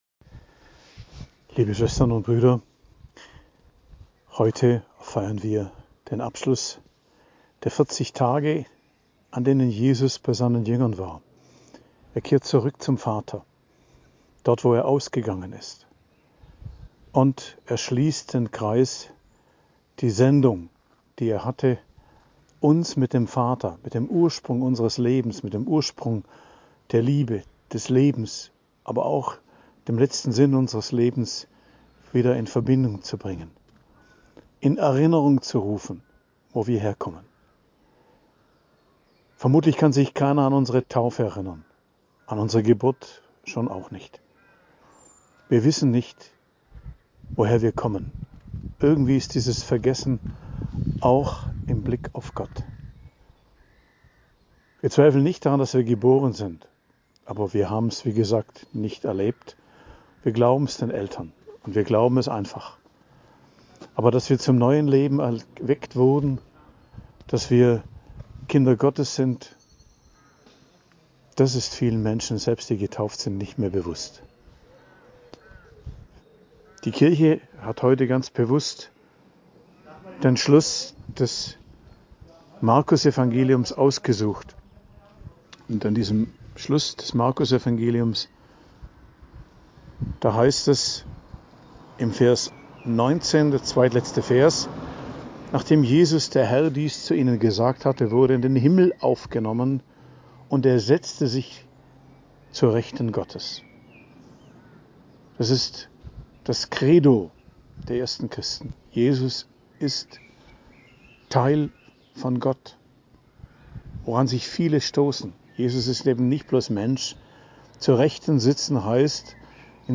Predigt am Hochfest Christi Himmelfahrt, 9.05.2024